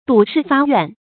赌誓发愿 dǔ shì fā yuàn
赌誓发愿发音
成语注音ㄉㄨˇ ㄕㄧˋ ㄈㄚ ㄧㄨㄢˋ